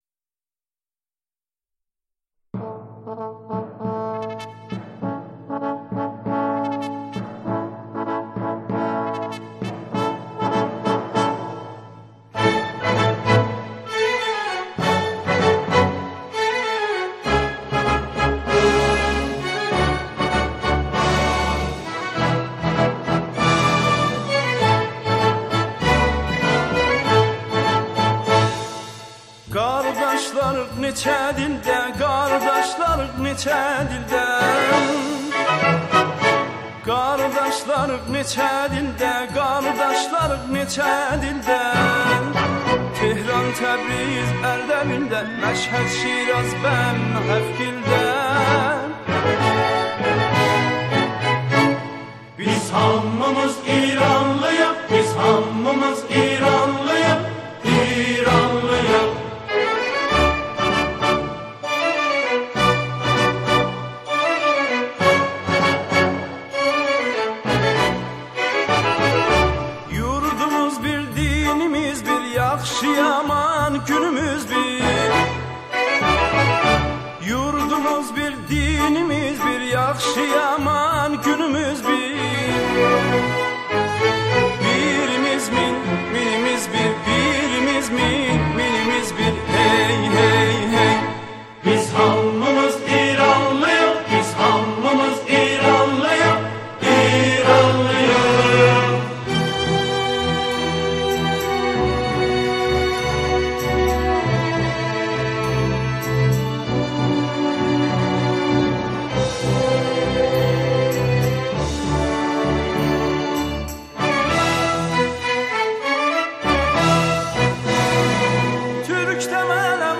گروهی از همخوانان